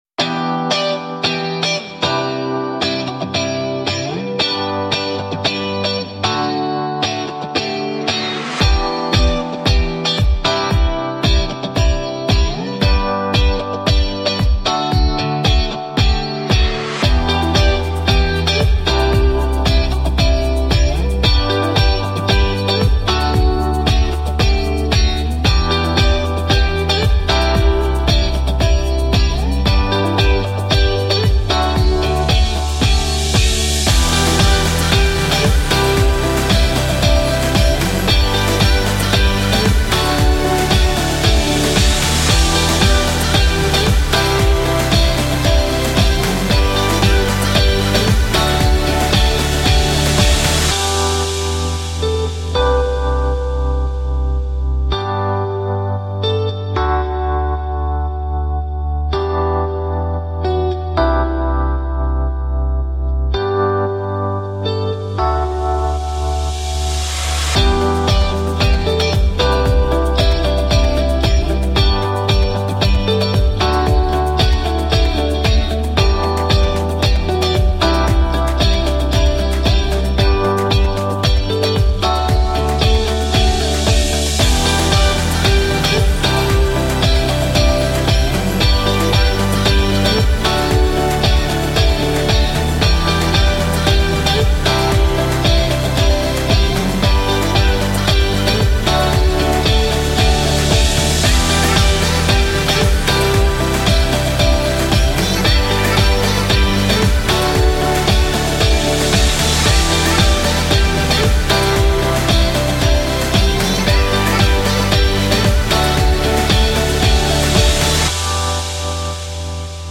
in Music Dance